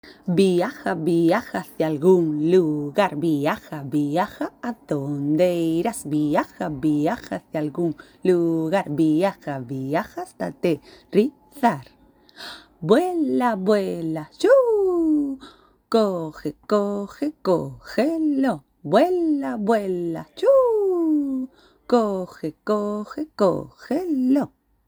Compás de amalgama.